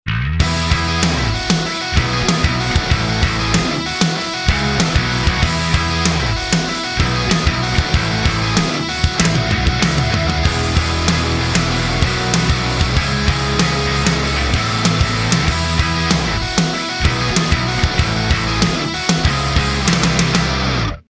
Snare Low (Song)